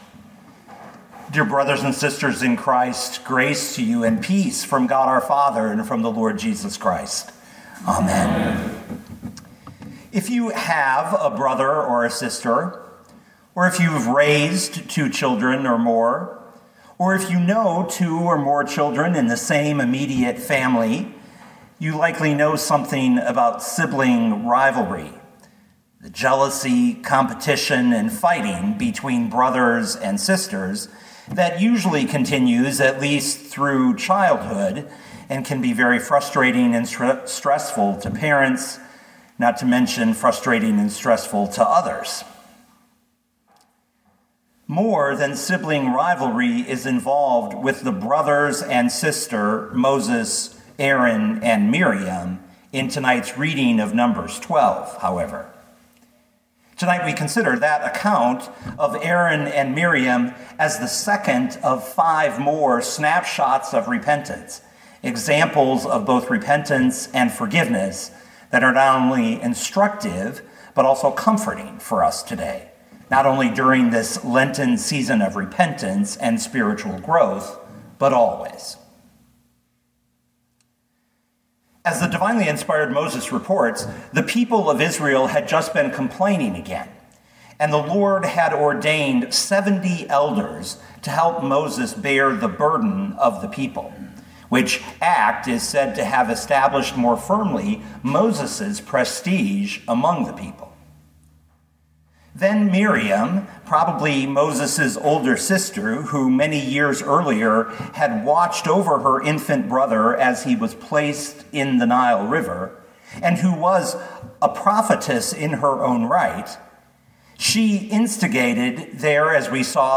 2022 Numbers 12:1-16 Listen to the sermon with the player below, or, download the audio.